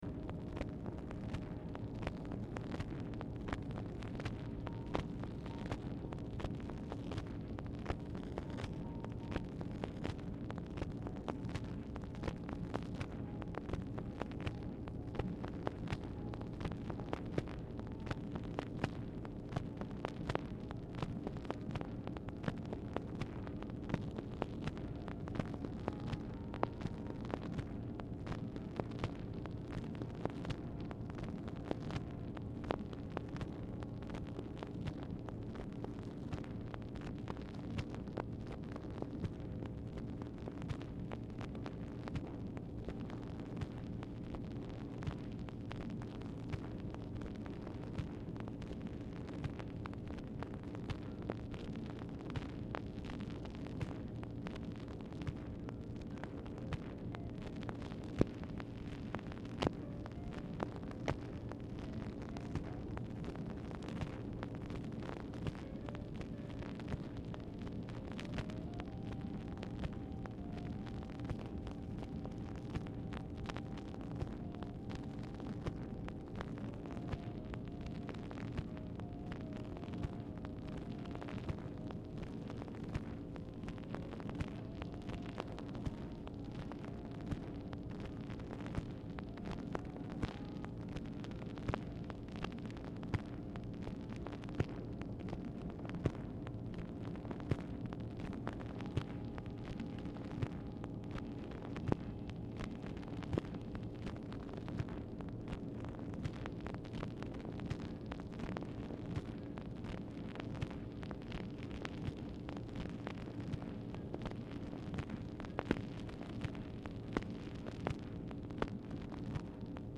Telephone conversation # 5213, sound recording, OFFICE NOISE, 8/25/1964, time unknown | Discover LBJ
MUSIC AUDIBLE IN BACKGROUND
Format Dictation belt
Oval Office or unknown location